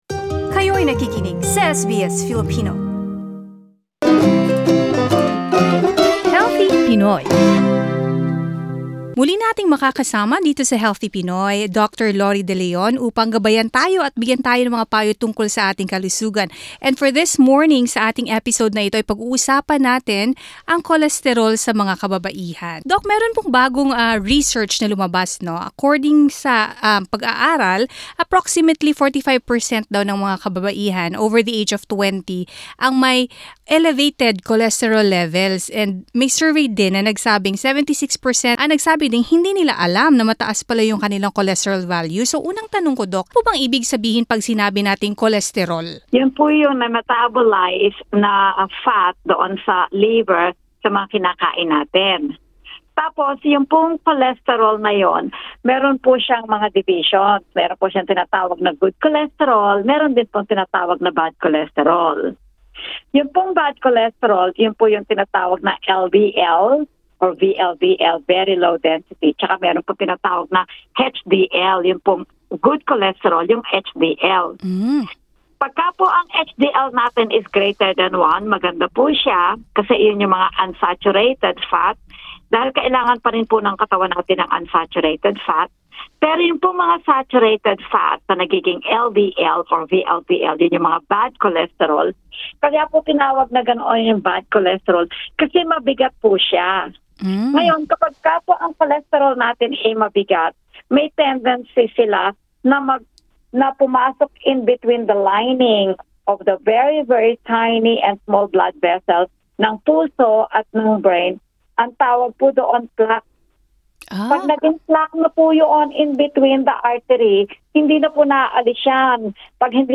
In an interview with SBS Filipino